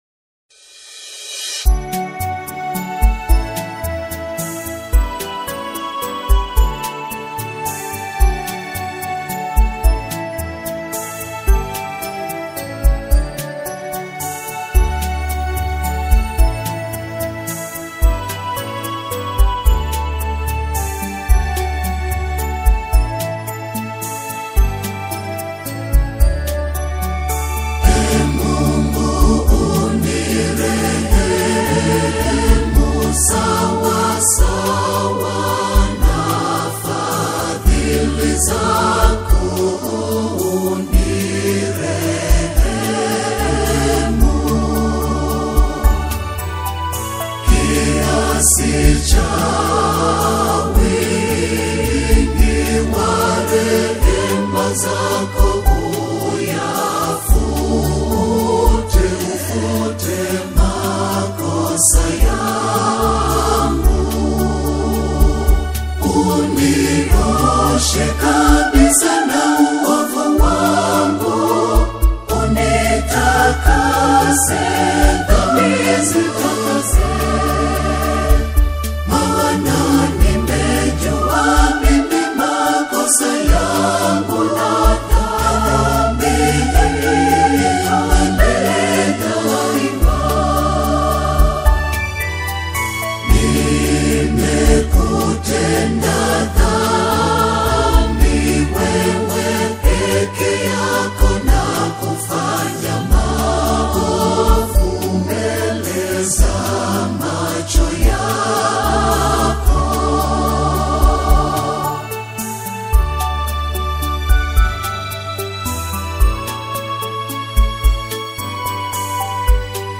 The exquisitely performed and vocally rich single